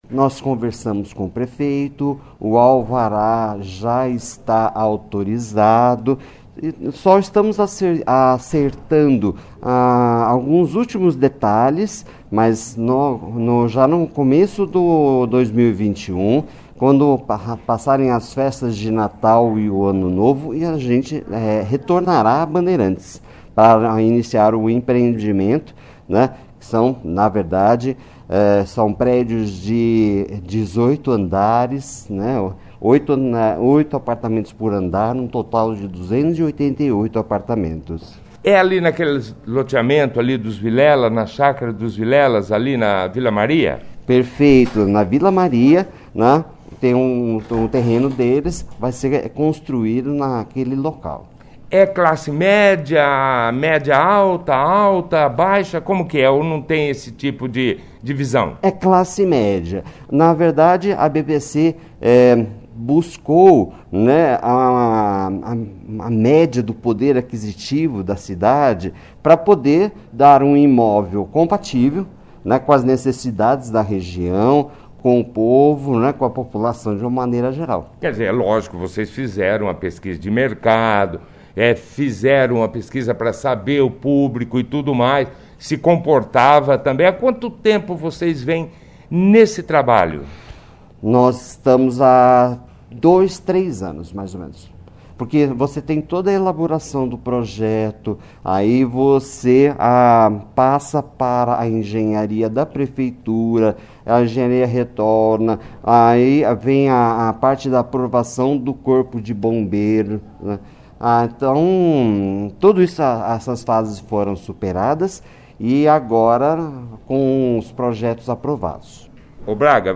participou da 1ª edição do jornal Operação Cidade desta quarta-feira, 23/12, falando detalhes do empreendimento.